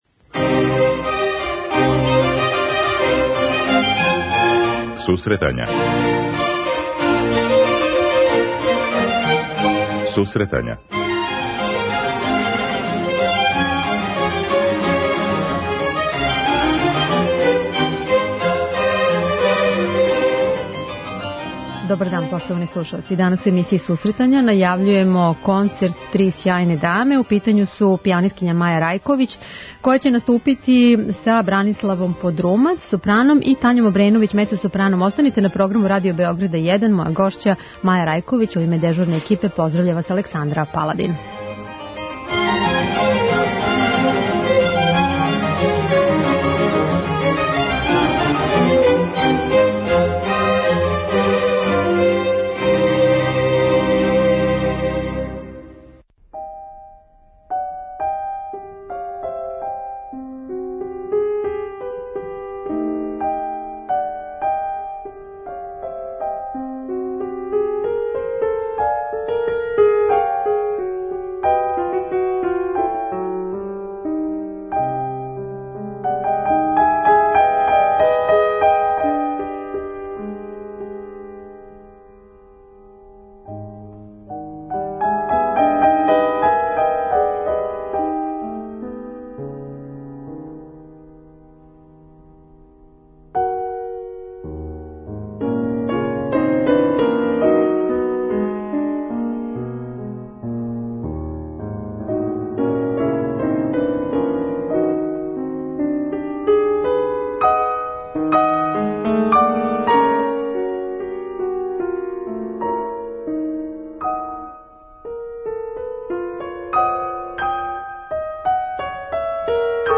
преузми : 9.86 MB Сусретања Autor: Музичка редакција Емисија за оне који воле уметничку музику.